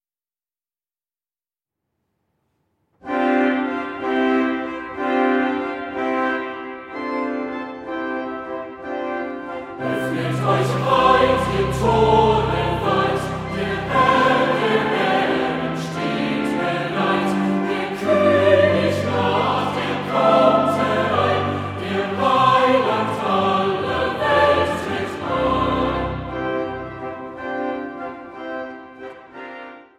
Advents- und Weihnachtsmusik
Orgel
eingespielt in der Michaeliskirche Leipzig